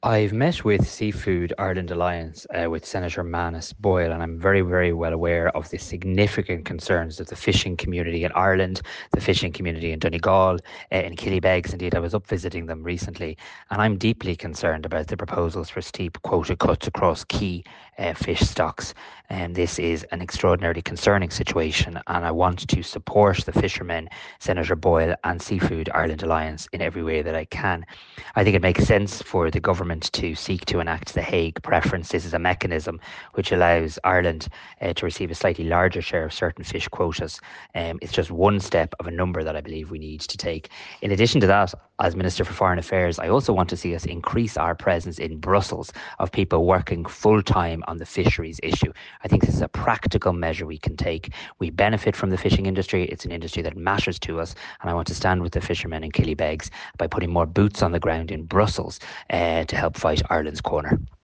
The Tanaiste says more voices are needed at a European level to protect the Irish fishing industry: